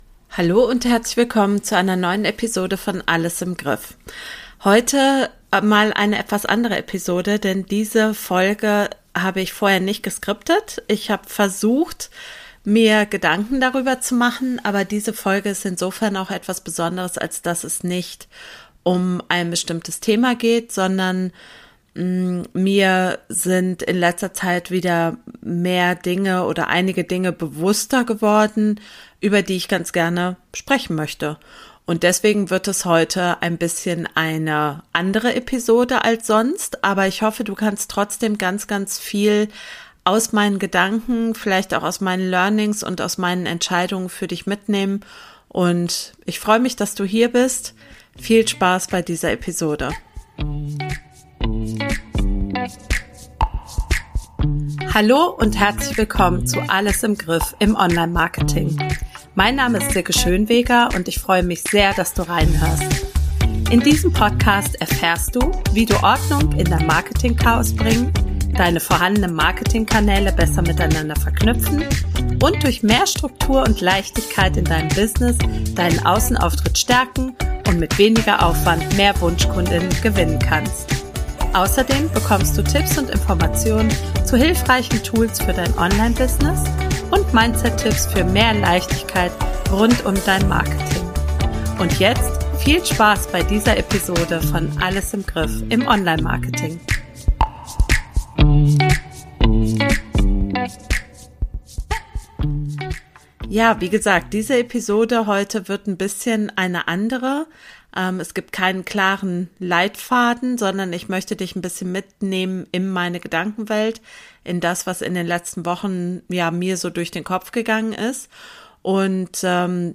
Diese Episode habe ich nicht vorab geskriptet.